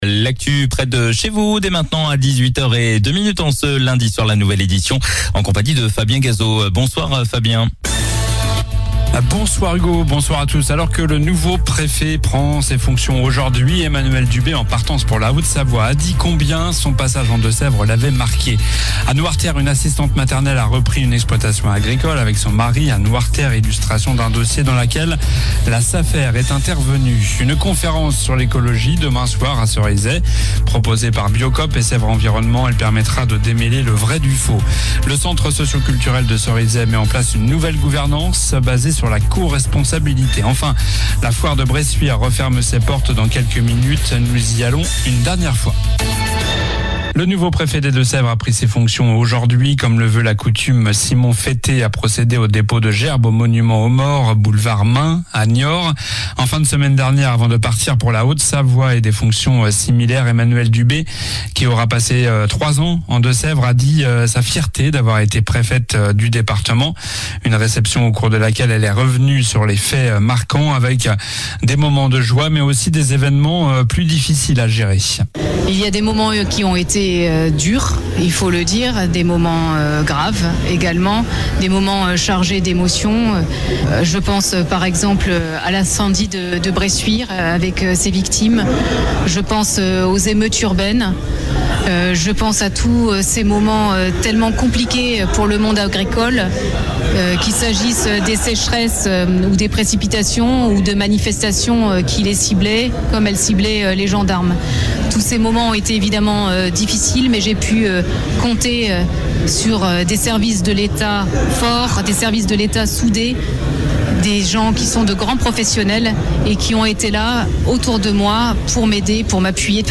Journal du lundi 7 avril (soir)